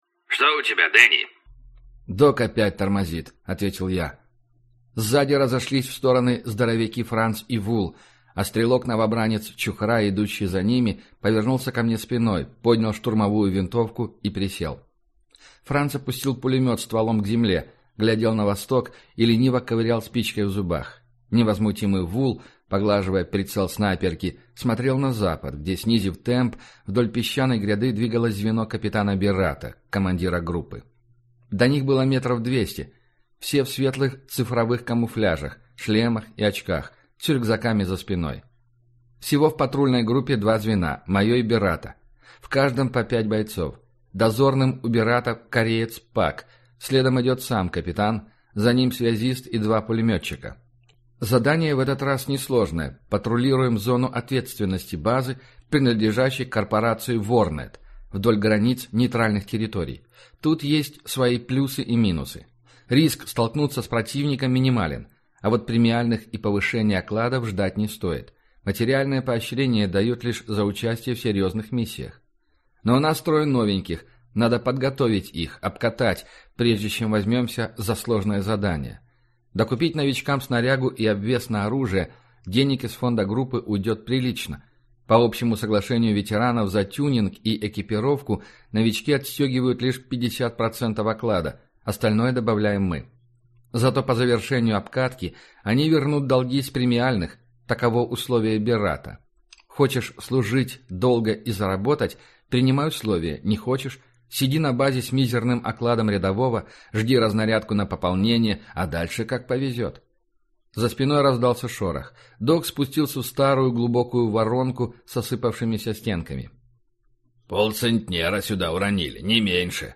Aудиокнига Туман войны